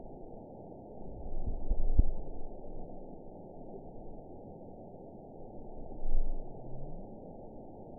event 910722 date 01/30/22 time 17:20:15 GMT (3 years, 3 months ago) score 7.96 location TSS-AB01 detected by nrw target species NRW annotations +NRW Spectrogram: Frequency (kHz) vs. Time (s) audio not available .wav